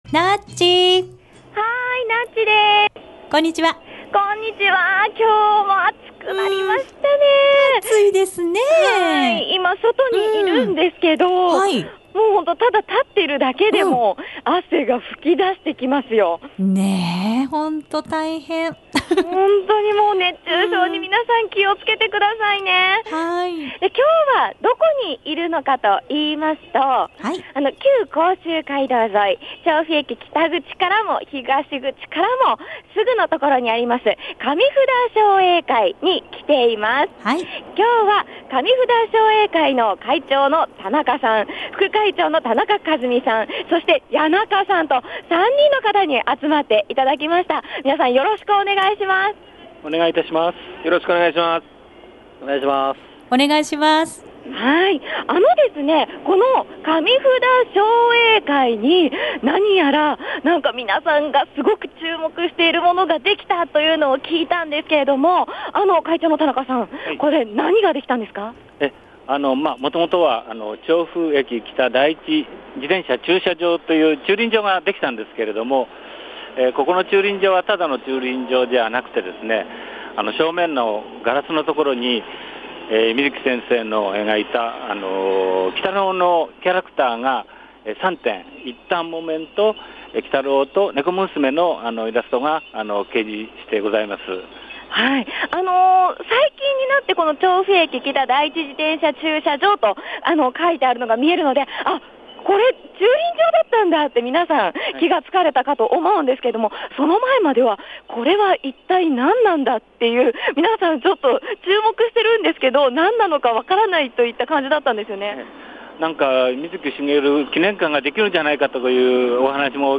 街角レポート
今日は外でレポートしたのですが、日陰で立っているだけで汗が吹き出してきましたよー！！